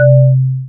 130.81+523.25+1318.5 fundamental with two harmonic overtones